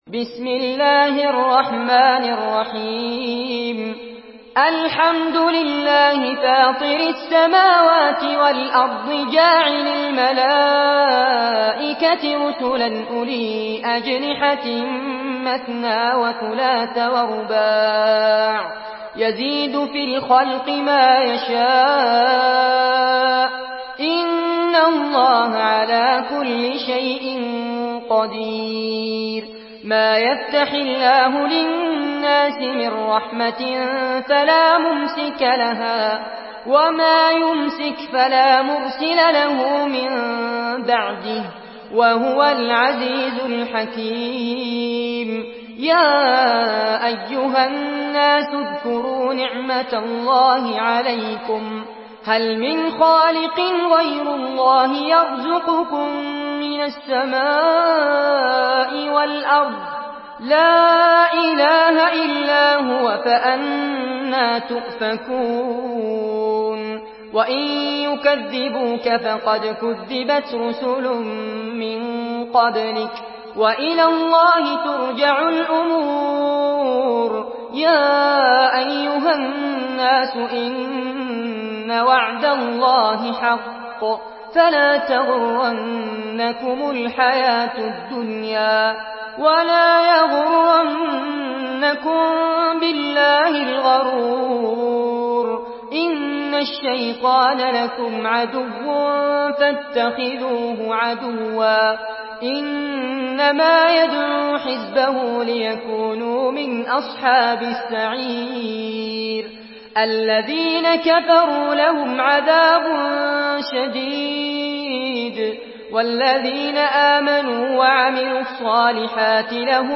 Surah ফাতের MP3 by Fares Abbad in Hafs An Asim narration.
Murattal Hafs An Asim